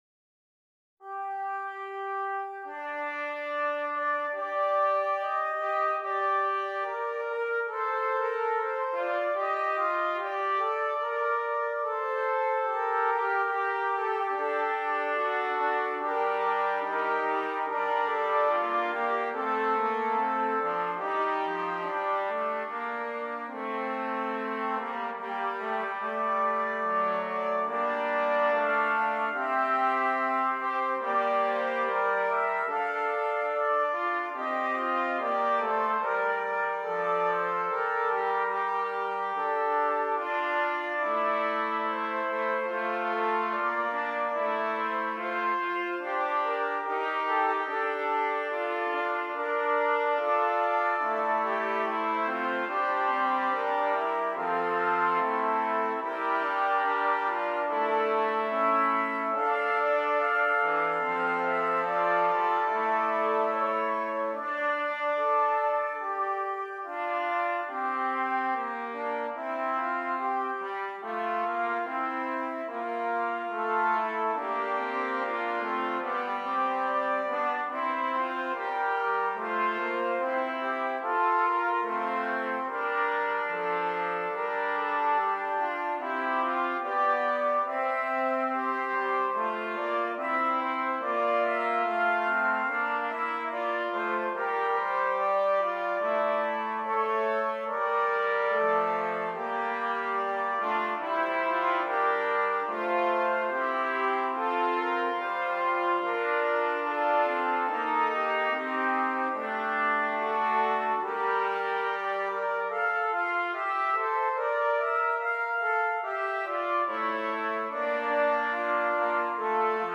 4 Trumpets